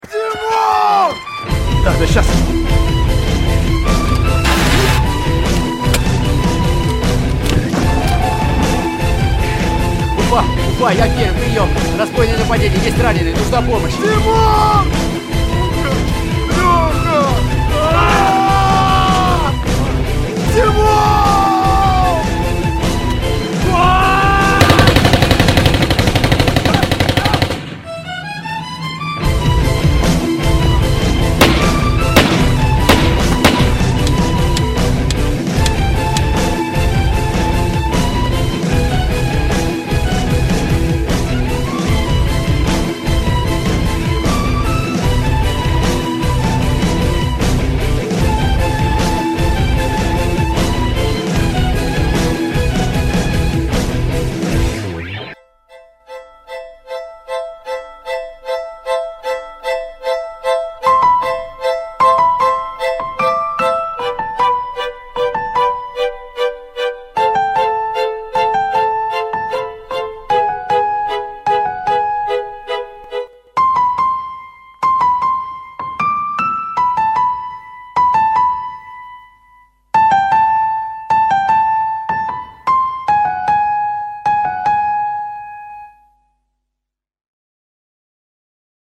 • Качество: 128, Stereo
саундтреки
скрипка
инструментальные
пианино
лирические
выстрелы